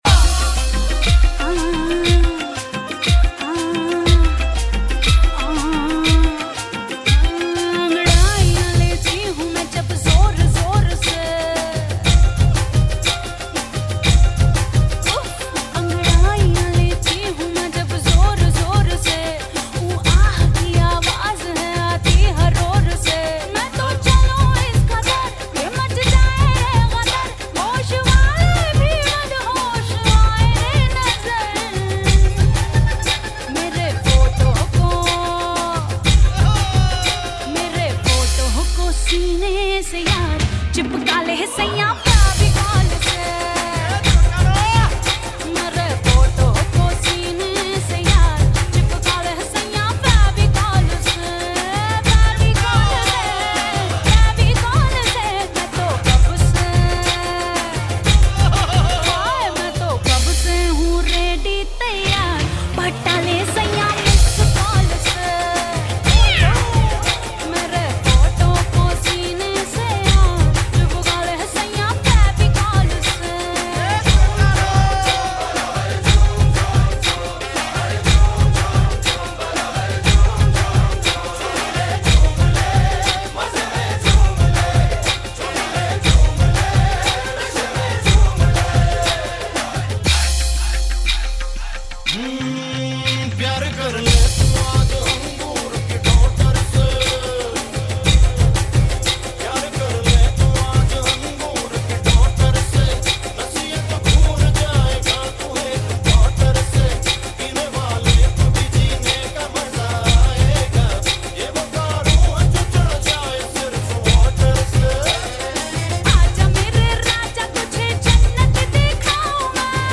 Gujarati Songs And Dandiya